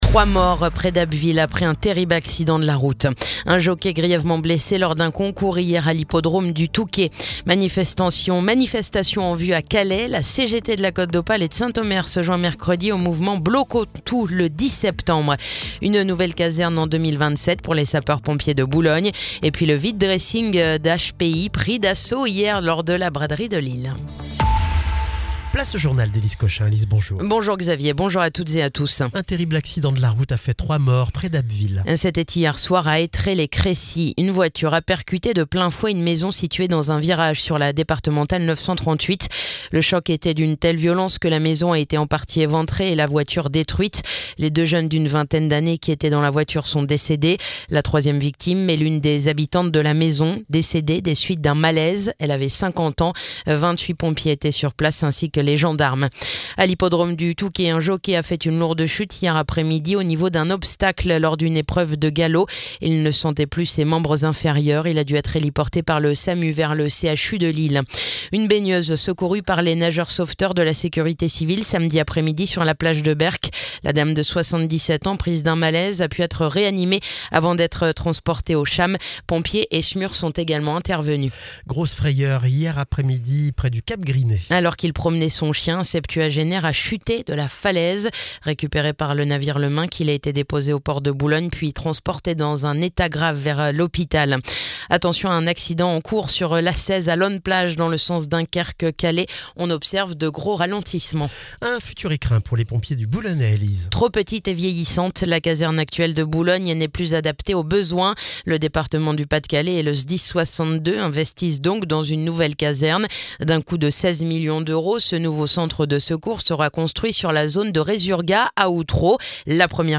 Le journal du lundi 8 septembre